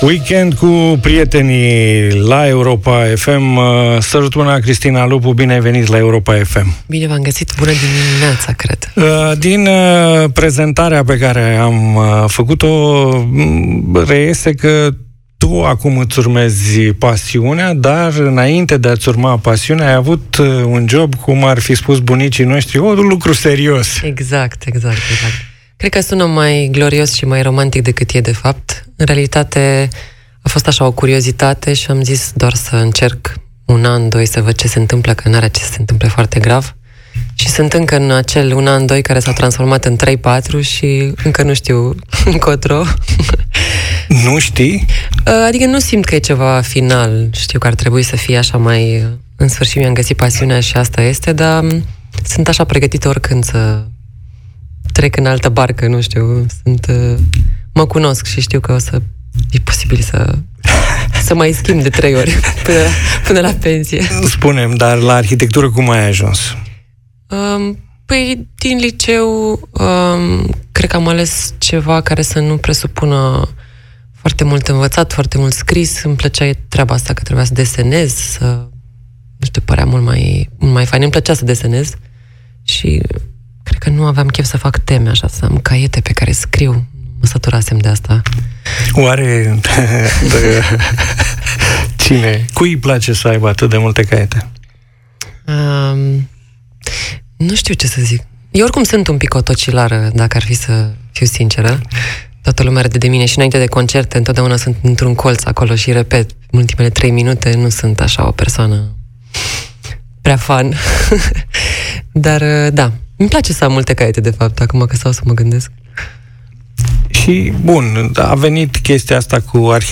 Cu o voce caldă și versuri sincere, ea reușește să creeze o legătură între ascultător și propriile sale trăiri.